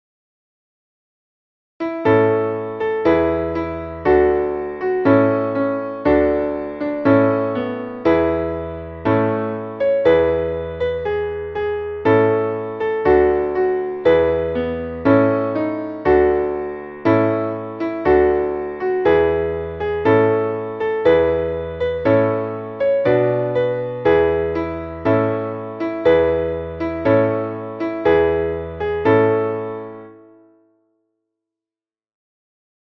Traditionelles Volks-/ Trinklied